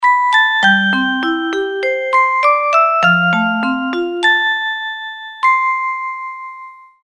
알림음 8_정각알림.mp3